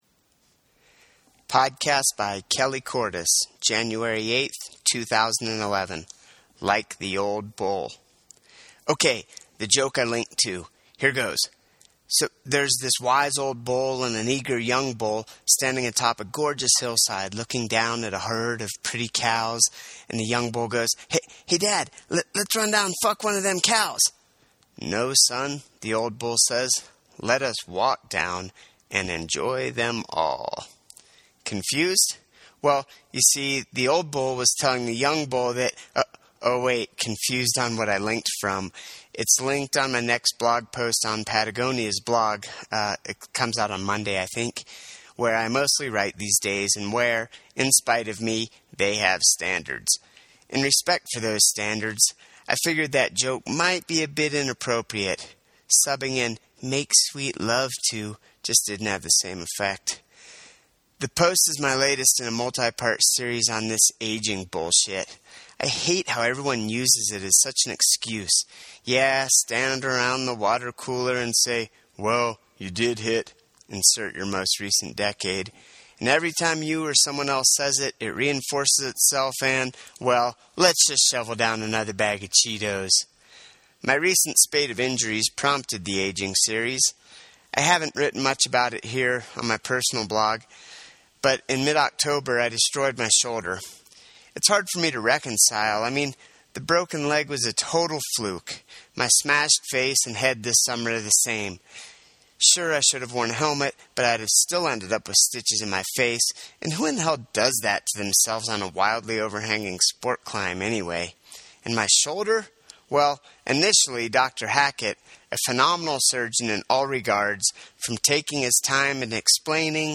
Homemade podcast test